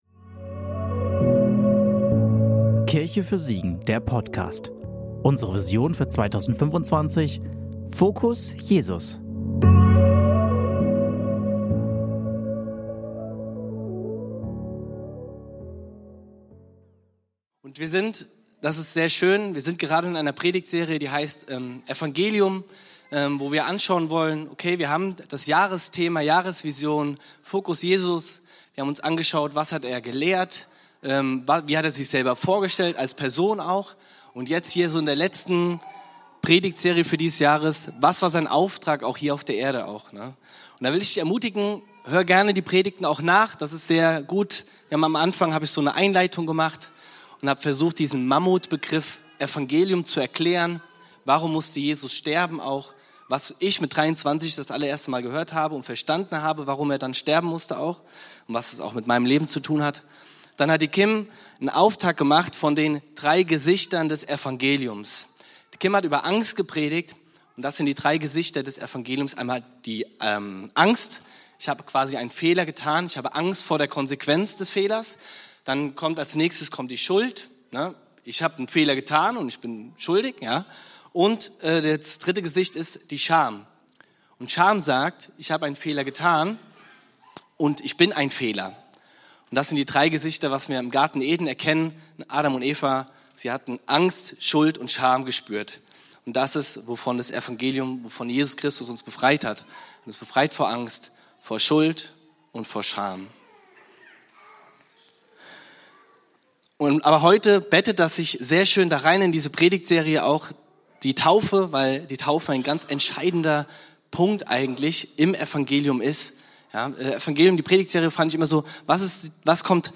Predigt vom 02.11.2025 in der Kirche für Siegen